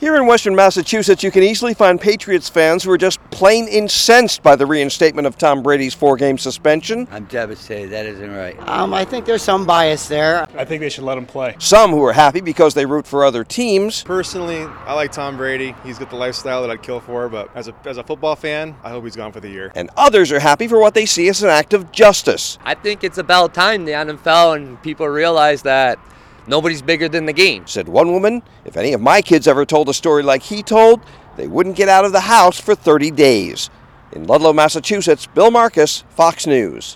Ludlow, Massachusetts rest stop on Interstate 90 where people reacted to Tom Brady suspension.